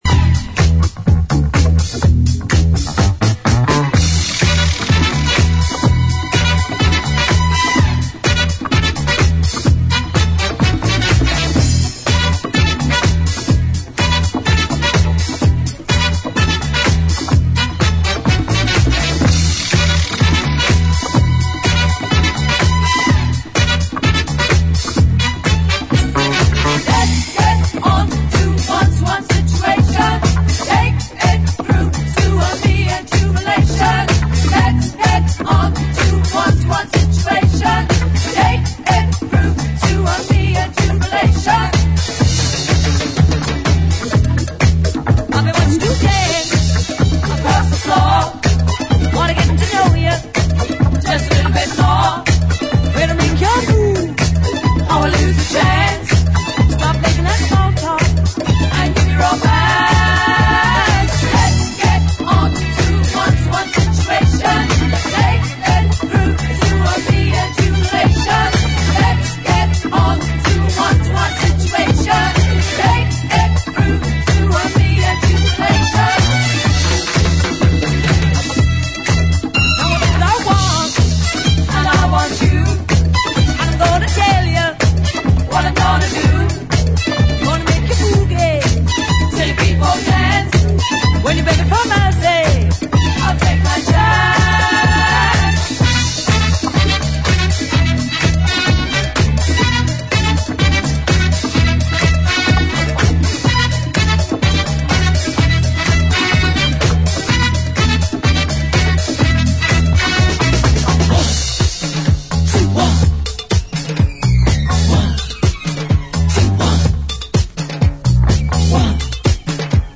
ジャンル(スタイル) HOUSE / DISCO / RE-EDIT